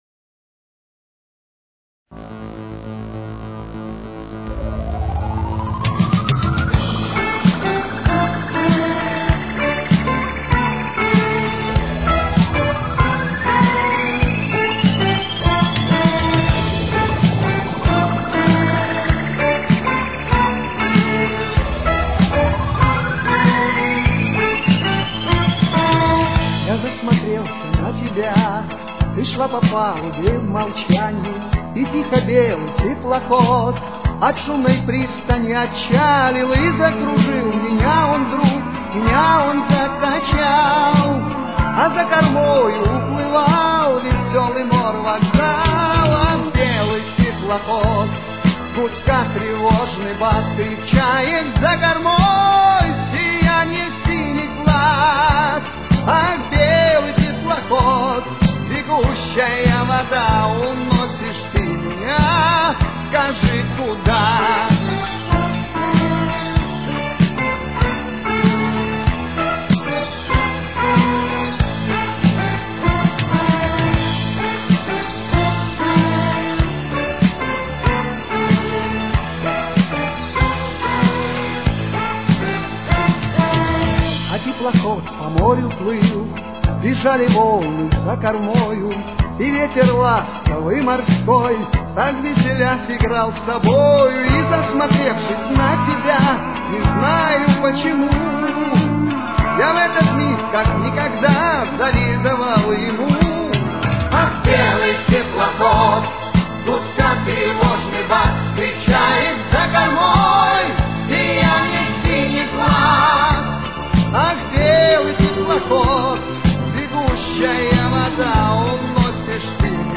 Тональность: Ля минор. Темп: 97.